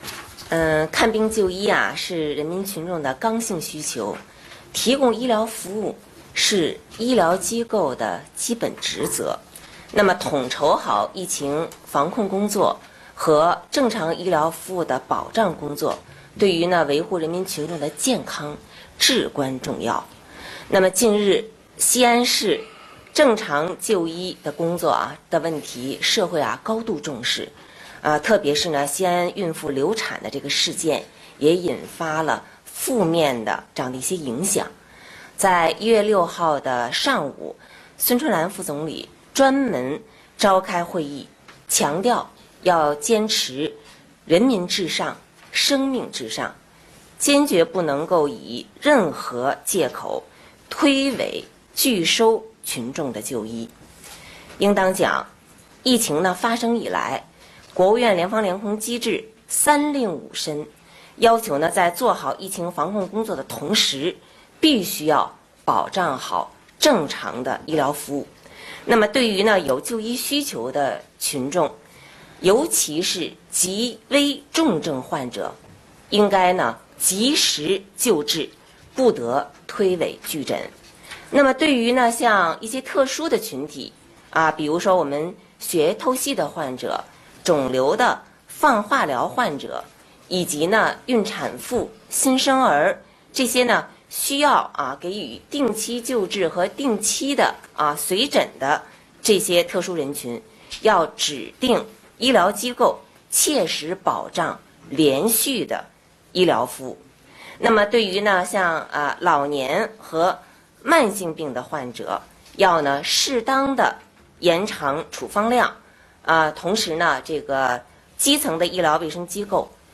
新闻发布会现场
国家卫生健康委医政医管局监察专员郭燕红